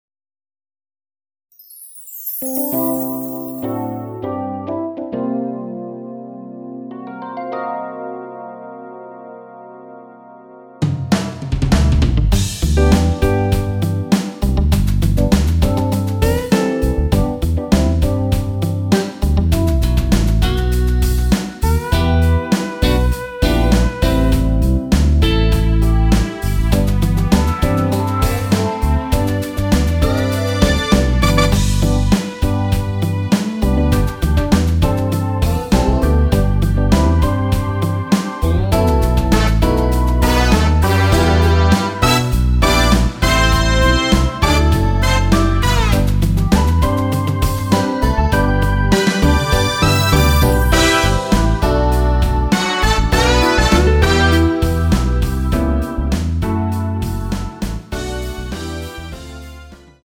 원키에서(+2)올린 MR입니다.(미리듣기 확인)
◈ 곡명 옆 (-1)은 반음 내림, (+1)은 반음 올림 입니다.
앞부분30초, 뒷부분30초씩 편집해서 올려 드리고 있습니다.